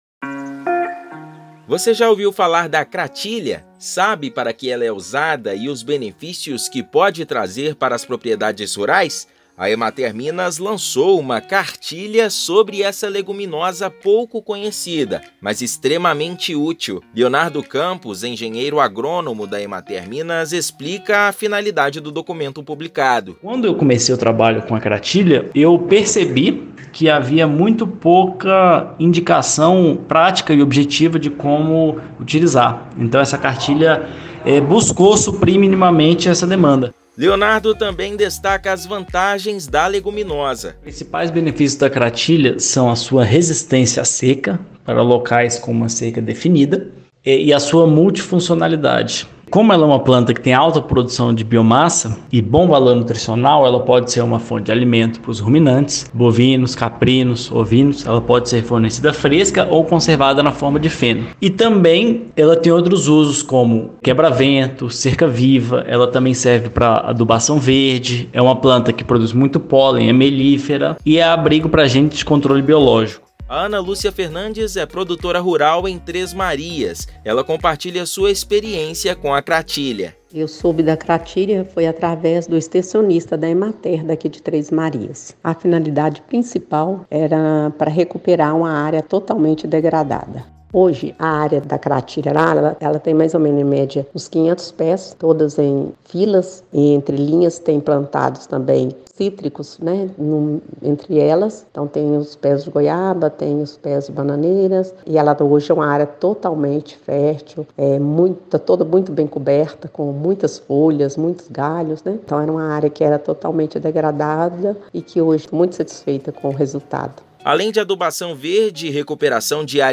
Agência Minas Gerais | [RÁDIO] Emater lança cartilha sobre a cratília, planta multifuncional que pode transformar propriedades rurais
Leguminosa apresenta grande resistência à seca e é de fácil manejo. Ouça matéria de rádio.